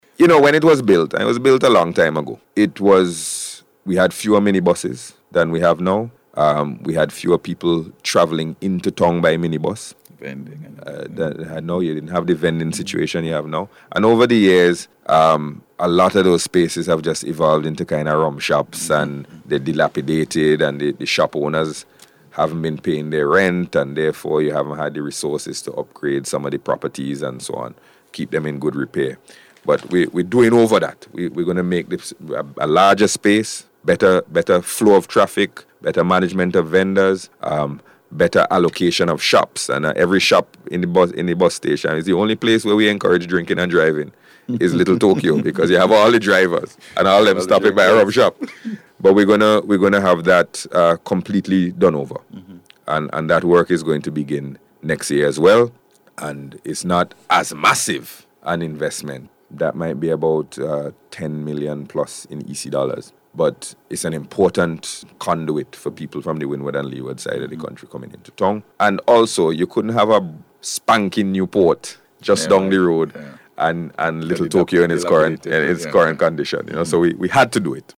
Speaking on NBC’s Face to Face programme this week, he noted that the Bus Terminal needs to be upgraded.